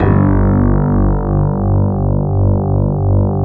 12 SJ BASS-L.wav